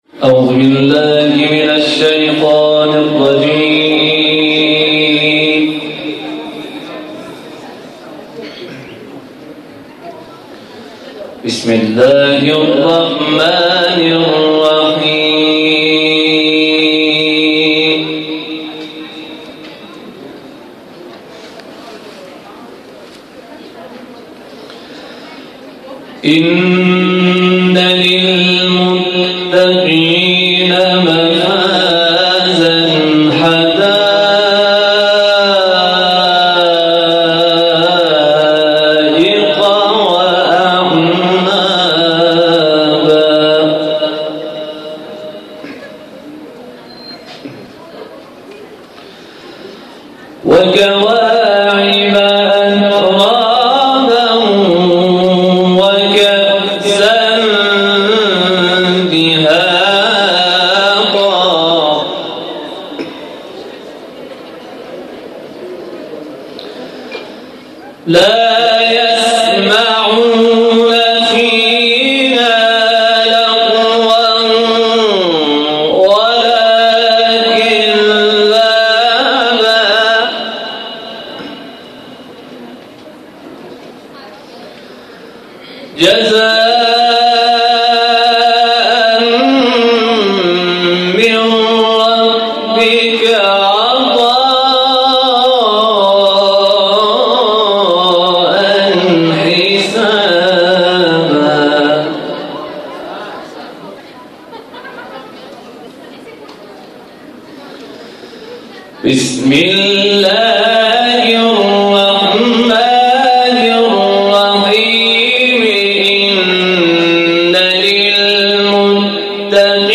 جشن میلاد مولود کعبه در پردیس ابوریحان دانشگاه تهران برگزار شد + صوت